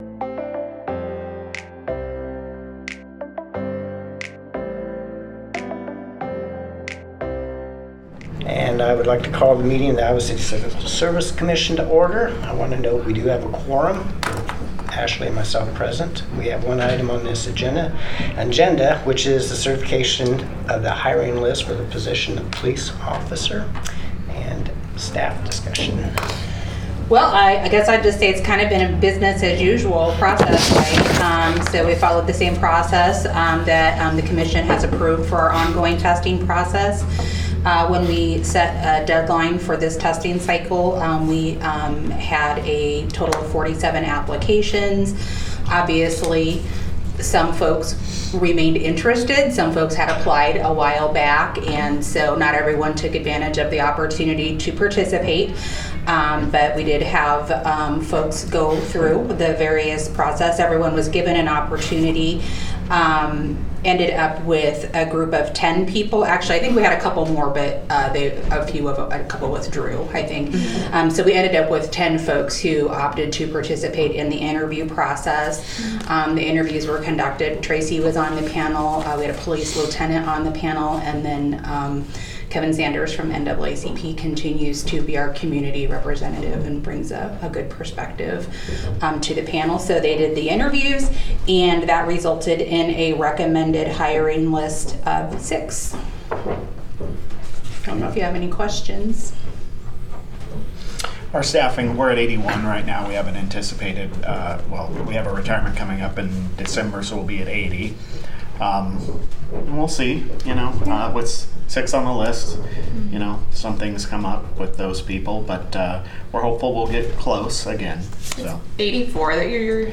A meeting of the City of Iowa City's Civil Service Commission.